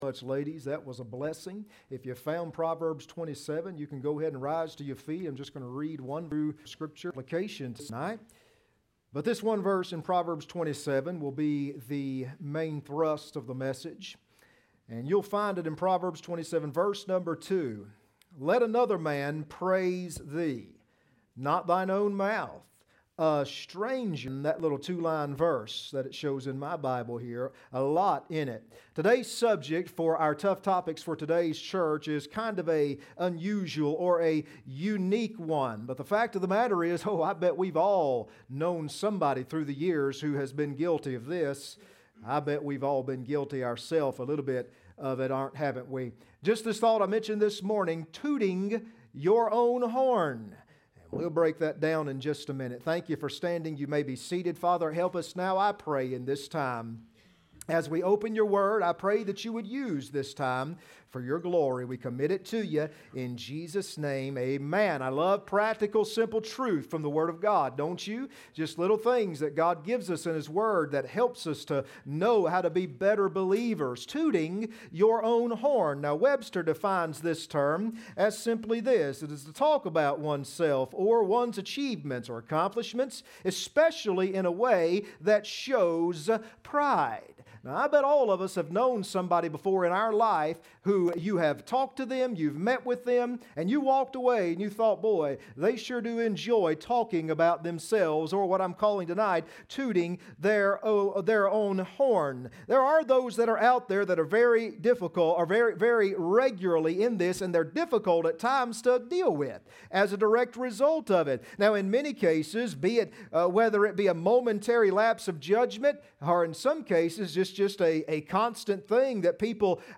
Proverbs 27:2 Service Type: Sunday Evening Next Sermon